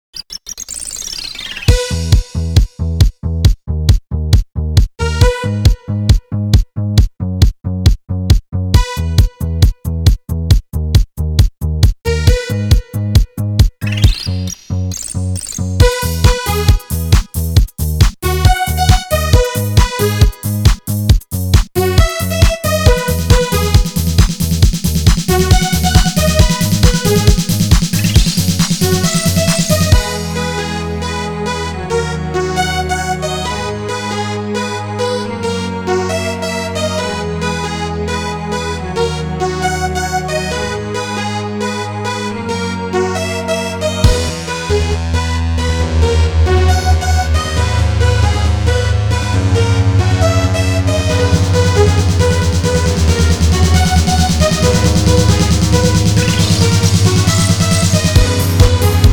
固件firm230328中，增加了新的“舞曲”功能，固件中给内置了30+首劲爆舞曲。
舞曲片段1